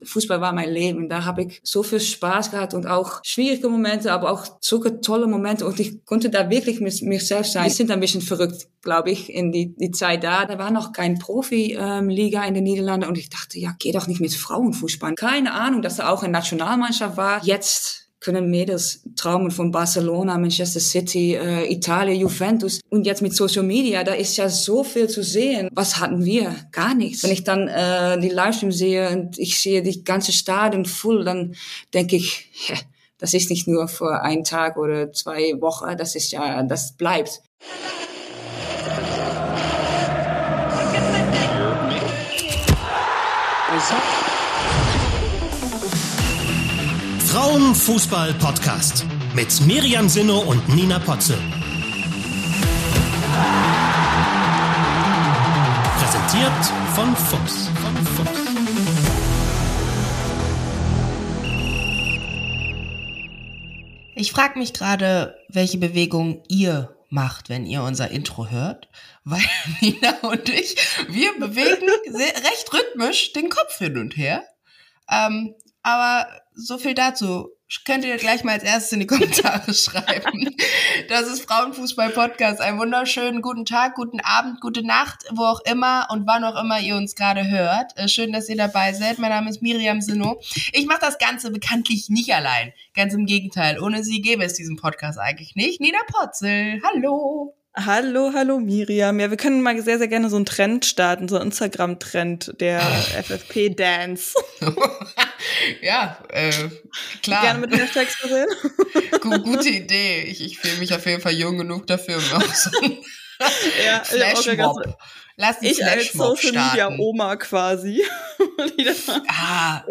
59 | "Fußball war mein Leben" | Europameisterin & Viktoria-Berlin-Co-Trainerin Anouk Dekker im Interview ~ Frauen. Fußball. Podcast. Podcast